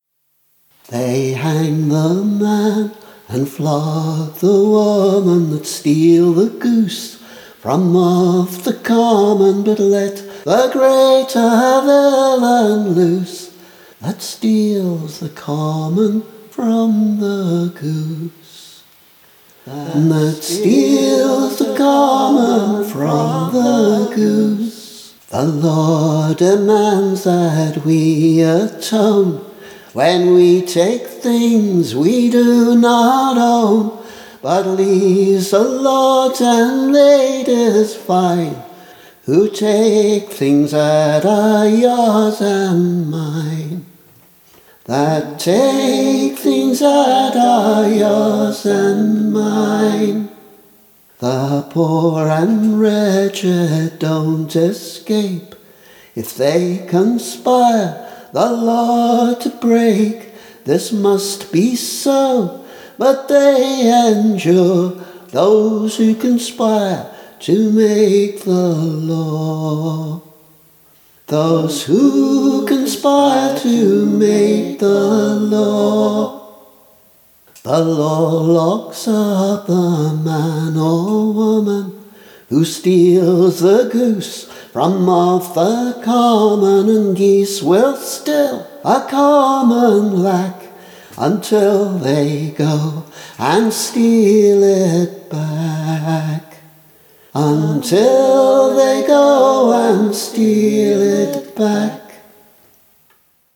Demo: They hang the man and flog the woman
There are a number of variations of this poem, which is usually assumed to date from the 1750s or ’60s, when enclosure legislation started to accelerate dramatically. The tune here is mine, recorded for the album ‘Cold Iron‘.